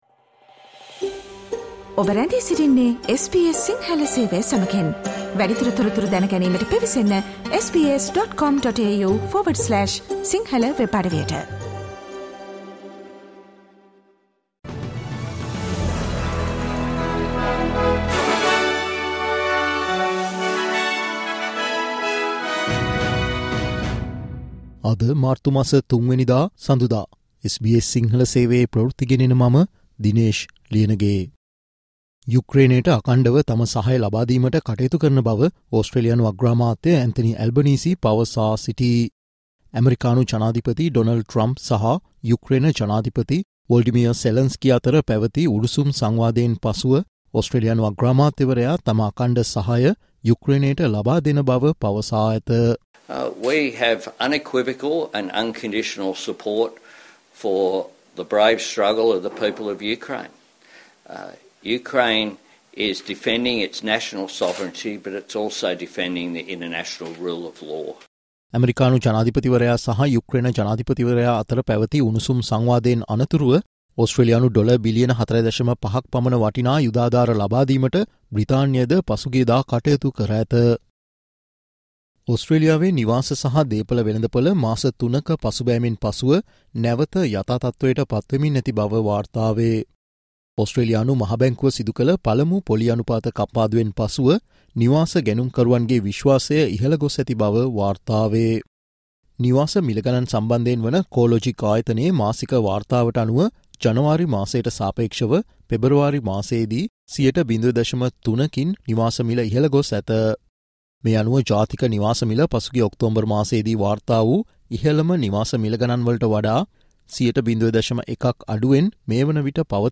Listen to today's SBS Sinhala Newsflash